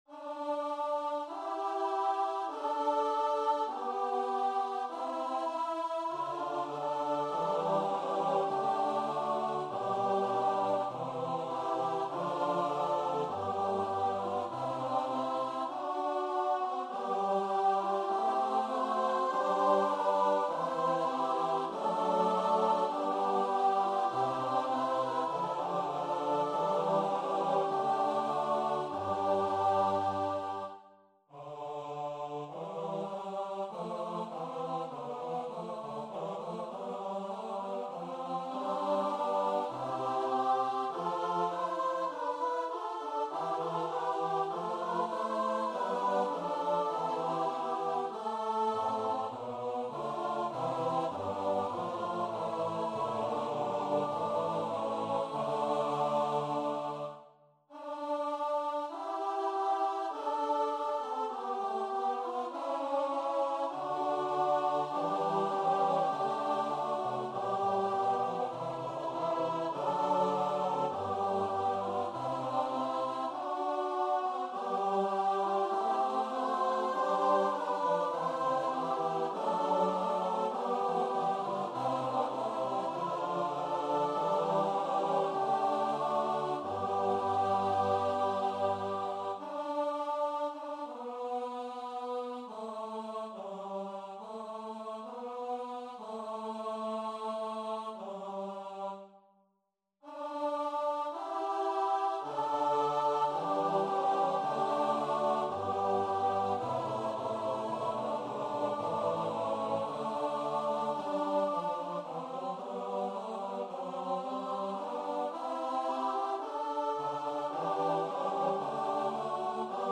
Scarlatti, Alessandro - Messa a quattro in canone, R.514/8 Free Sheet music for Choir (SATB)
Messa a quattro in canone, R.514/8 Choir version
Style: Classical